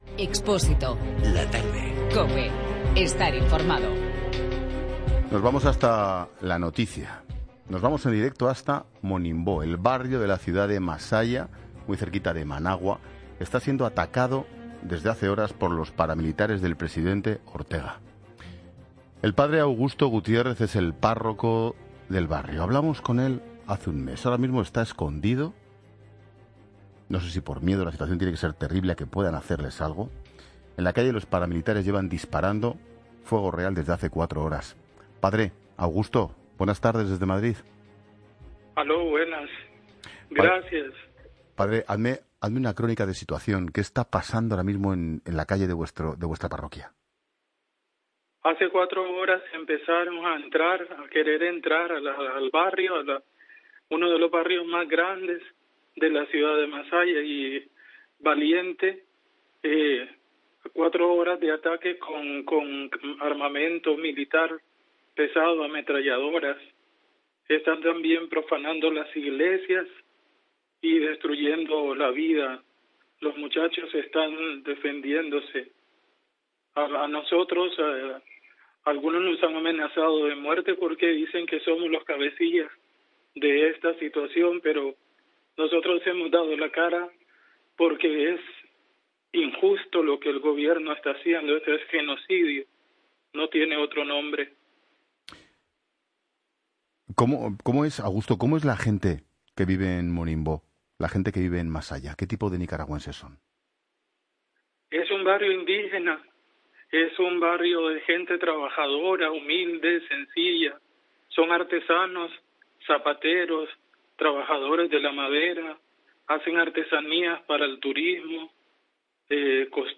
Por favor, escuchar el testimonio de este sacerdote, ayer martes 17 de julio, en una entrevista telefónica con el gran Ángel Expósito en La Tarde de Cope… Me quedo sin palabras.